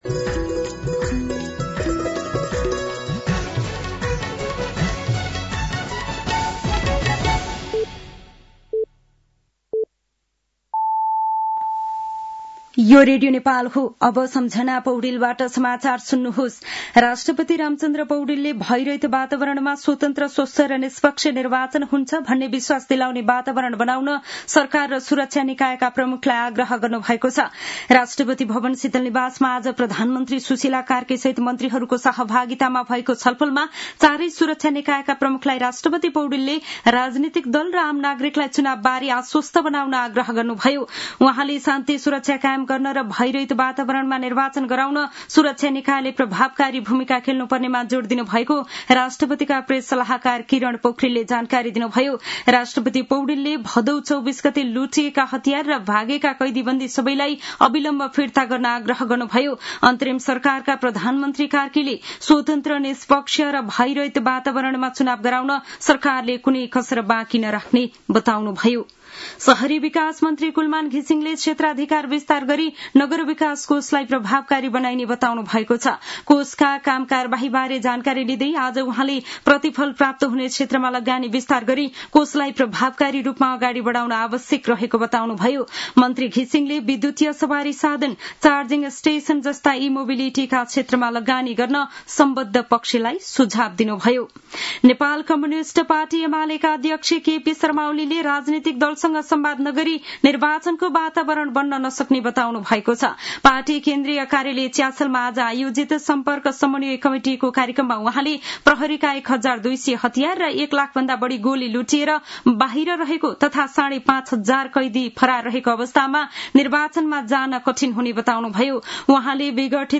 साँझ ५ बजेको नेपाली समाचार : १ कार्तिक , २०८२